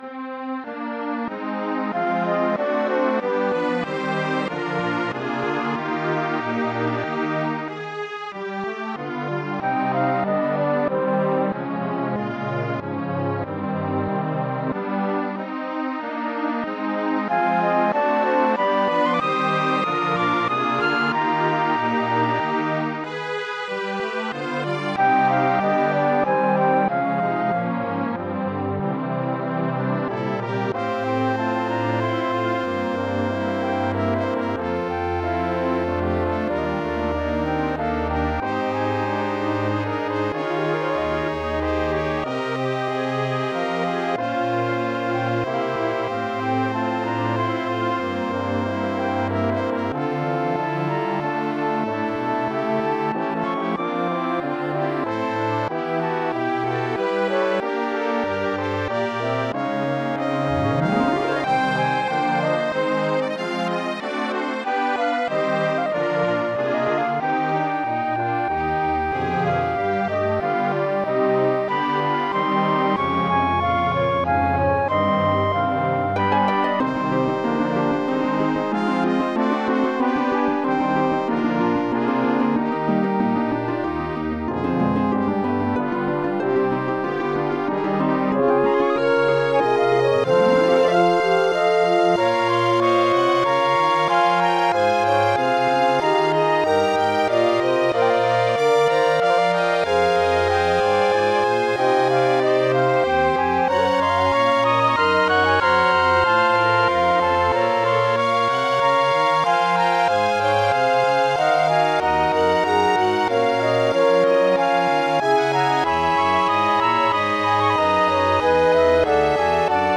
Style: Orchestral